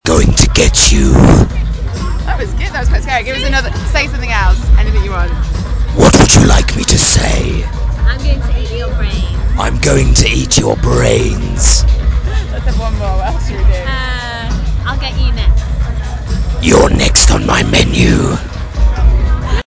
Scary voice man
53070-scary-voice-man.mp3